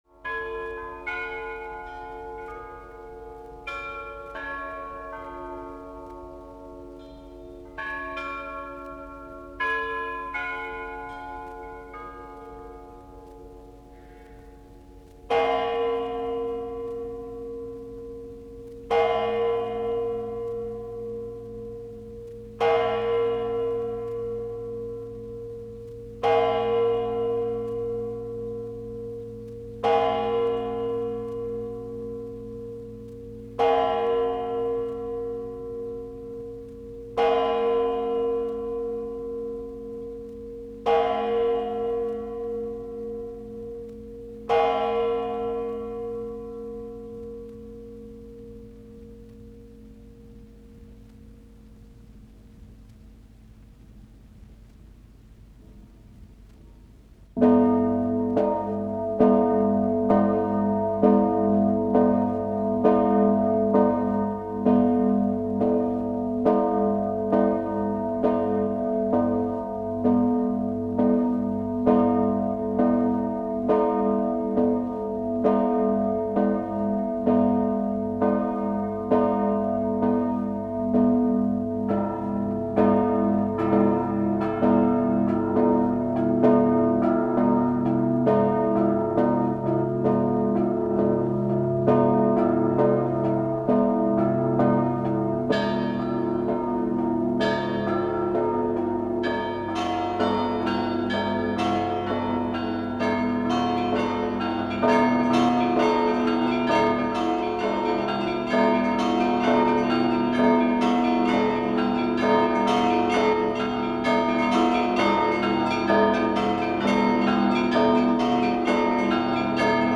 03_zvonnica_pskovo_pecherskogo_monastyrja.mp3